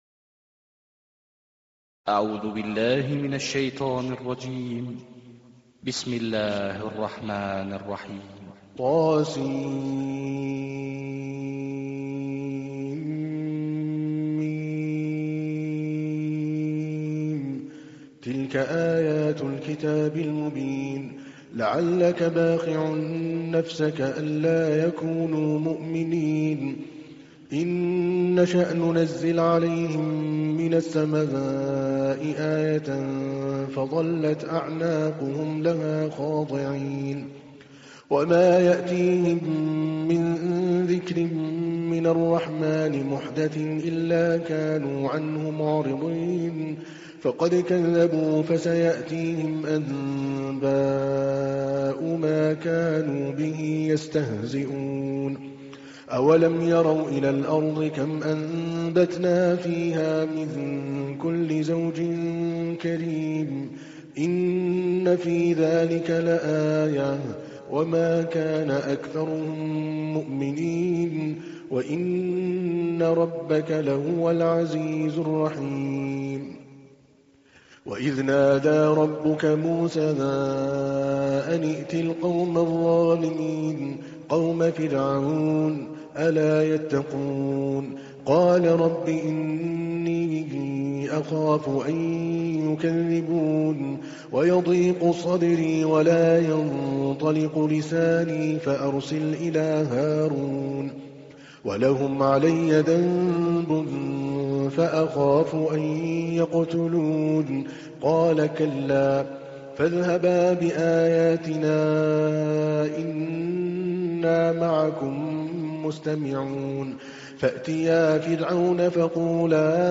تحميل : 26. سورة الشعراء / القارئ عادل الكلباني / القرآن الكريم / موقع يا حسين